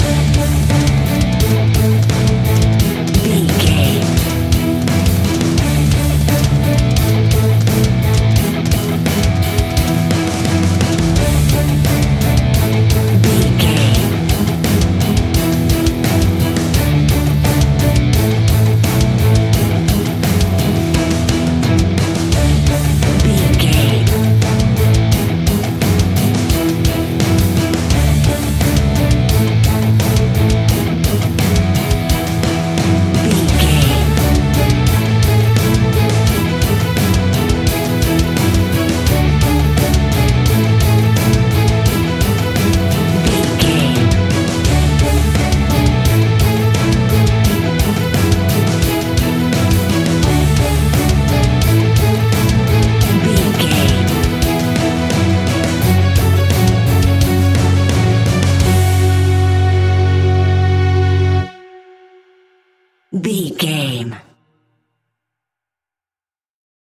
Epic / Action
Fast paced
Aeolian/Minor
F#
heavy rock
heavy metal
horror rock
instrumentals
Heavy Metal Guitars
Metal Drums
Heavy Bass Guitars